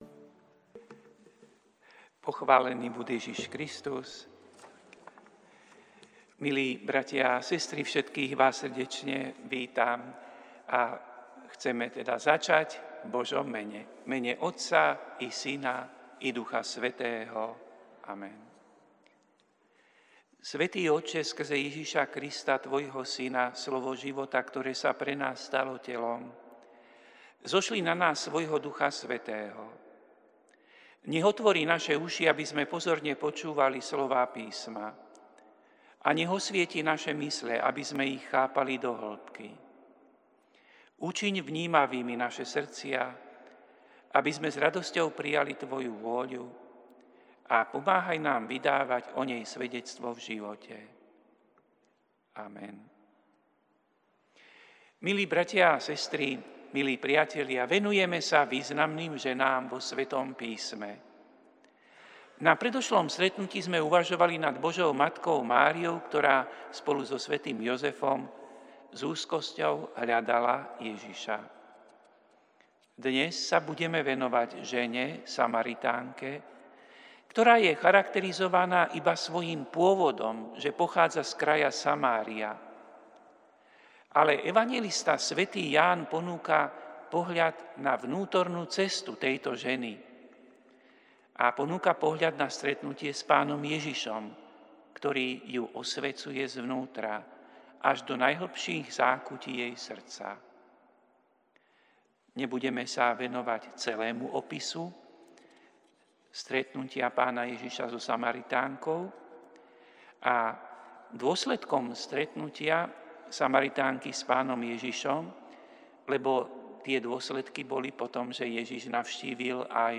Prinášame plný text a audio záznam z Lectio divina, ktoré odznelo v Katedrále sv. Martina 8. apríla 2026.